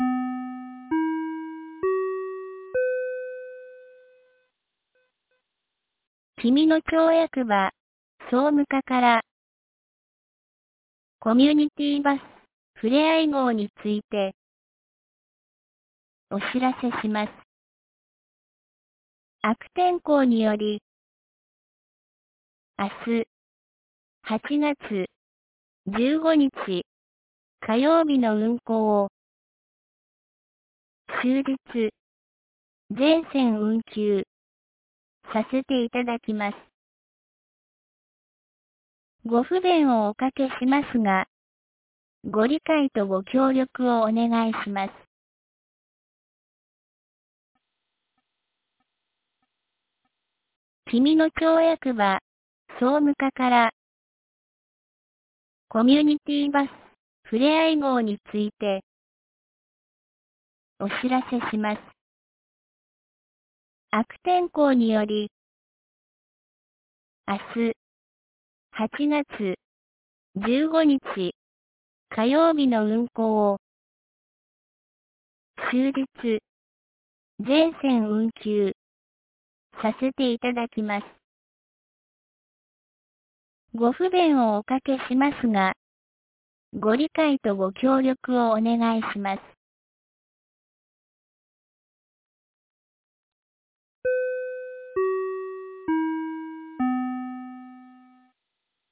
2023年08月14日 17時06分に、紀美野町より全地区へ放送がありました。